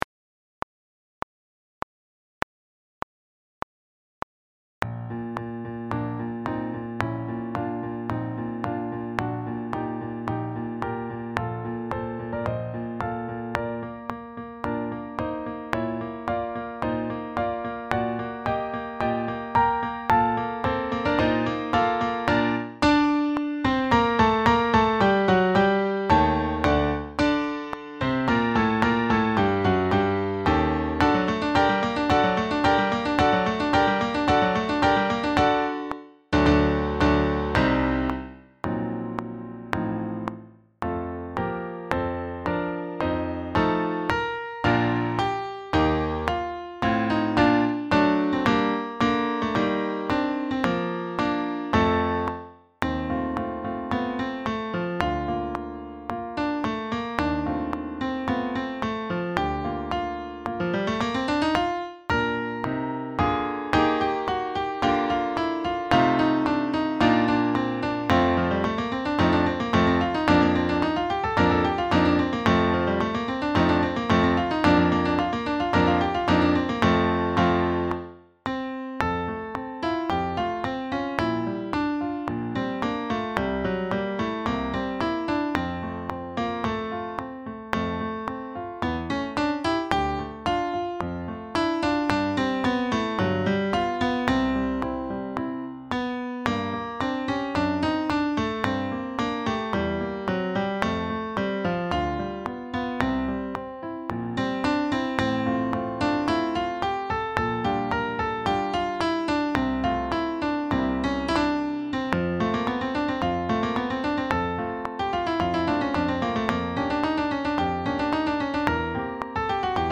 Sax Solo
This concerto in one movement, for Bb tenor saxophone (or Bb soprano), was written just a few years after the saxophone's birth.
Backing track